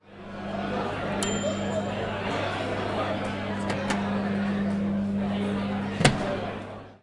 食堂里的微波炉
描述：这是在Pompeu Fabra大学食堂录制的微波炉的声音，用变焦H4录制。它有微波炉停止时的管道声，以及接下来门被打开和关闭时的声音。这是在古腾堡广场上录制的，